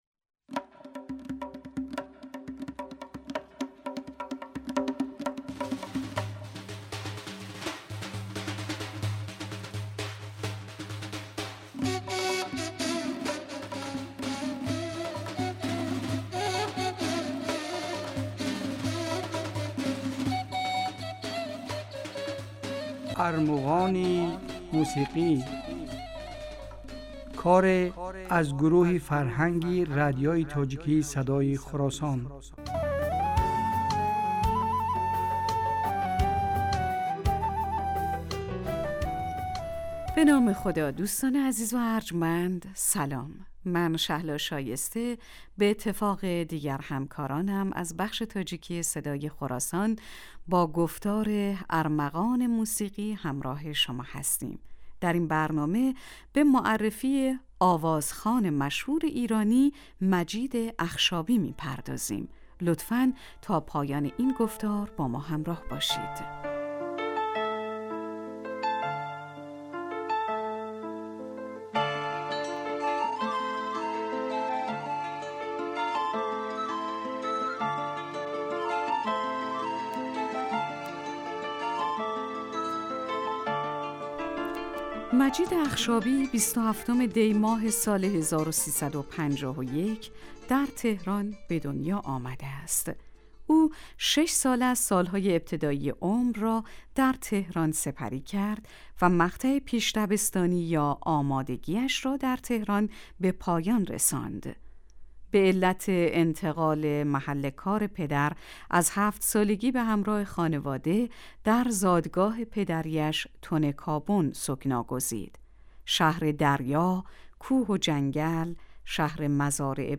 Армуғони мусиқӣ асари аз гурӯҳи фарҳанги радиои тоҷикии Садои Хуросон аст.